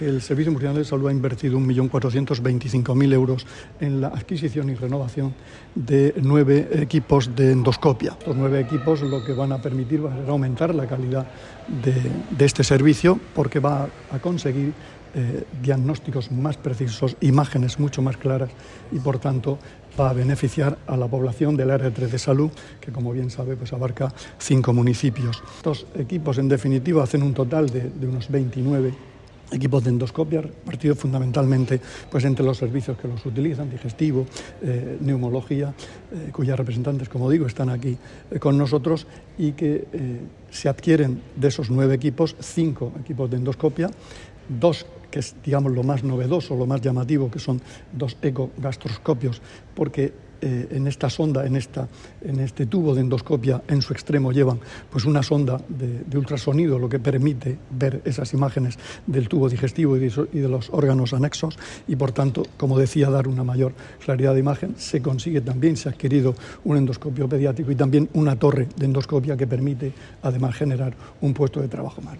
Declaraciones del consejero de Salud durante su visita al hospital Rafael Méndez de Lorca.